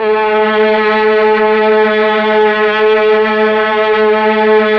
Index of /90_sSampleCDs/Roland LCDP09 Keys of the 60s and 70s 1/STR_Melo.Strings/STR_Tron Strings